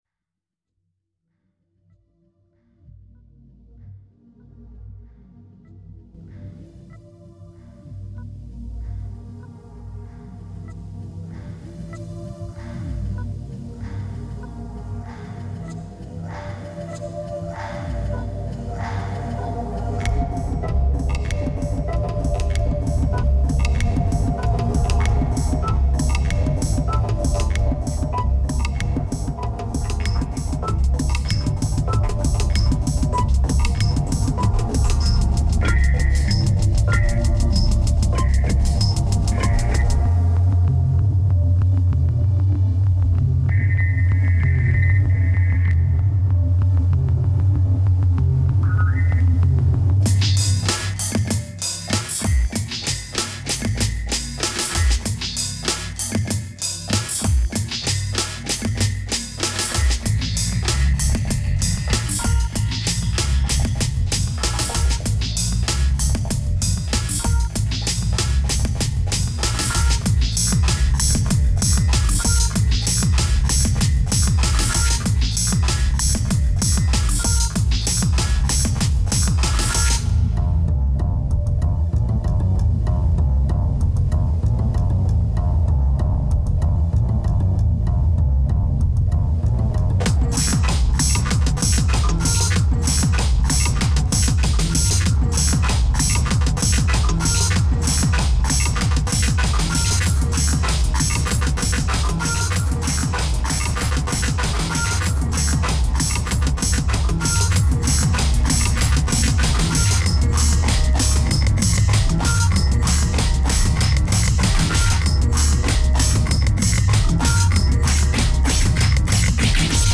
2271📈 - -79%🤔 - 96BPM🔊 - 2009-03-13📅 - -509🌟